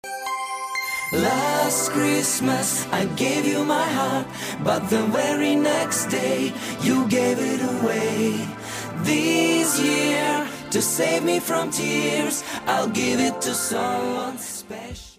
поп
спокойные
Cover
рождественские
стильный Boys Band.